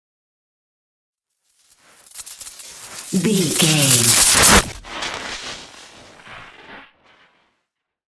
Appear sci fi electricity
Sound Effects
Atonal
futuristic
high tech
whoosh